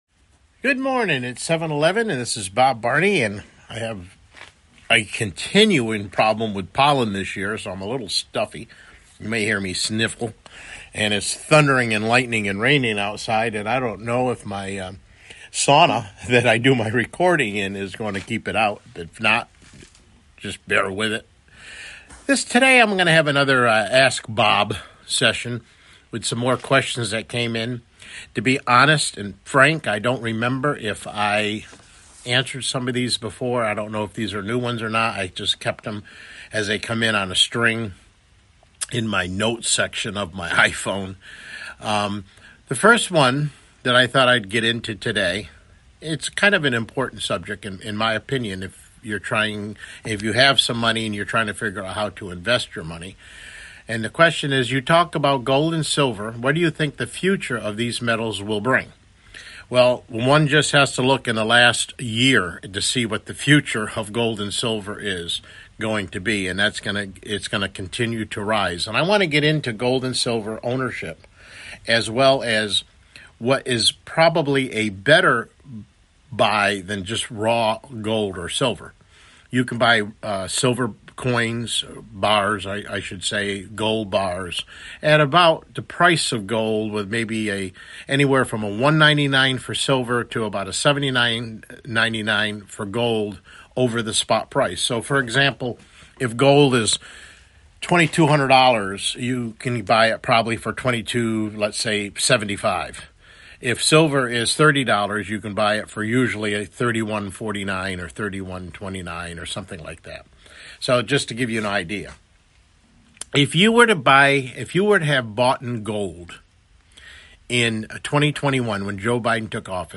CLICK HERE TO LISTEN TO THE PLAIN TRUTH TODAY MIDDAY BROADCAST: Silver, Gold, EMP’s and Stolen Elections | June 2023